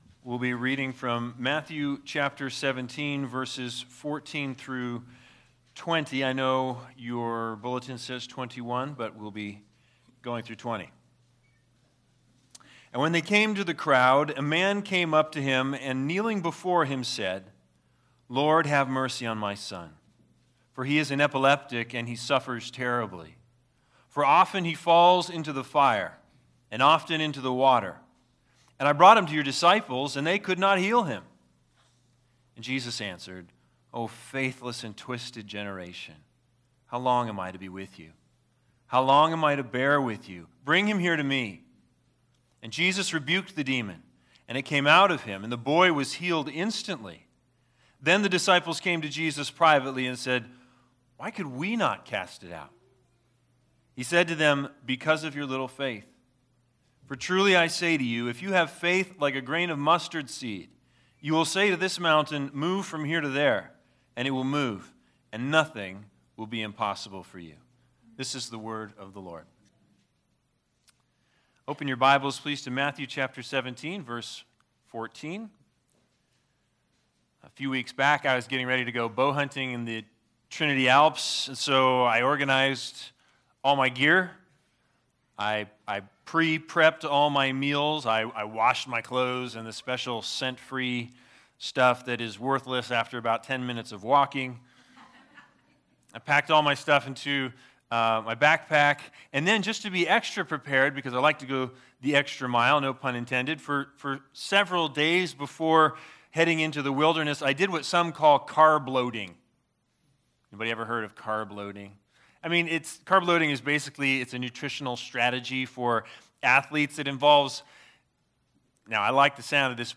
Matthew 17:14-21 Service Type: Sunday Sermons The big idea